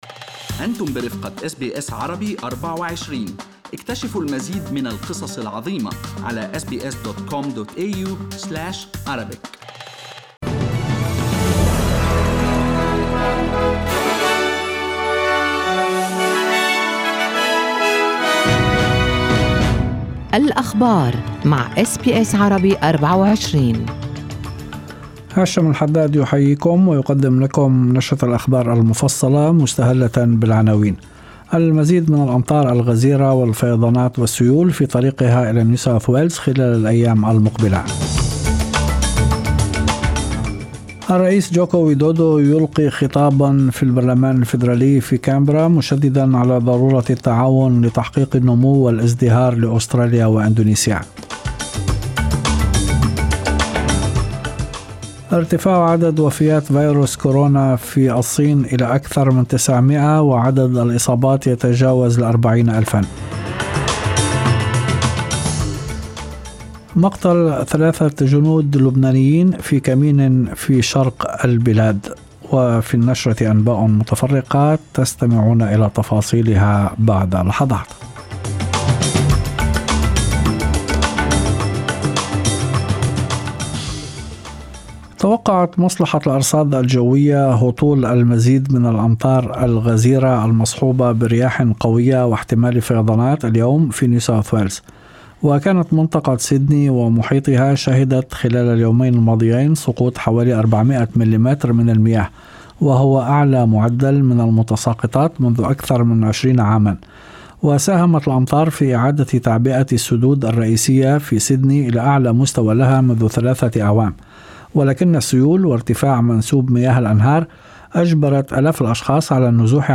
Arabic News Bulletin Source: SBS Arabic24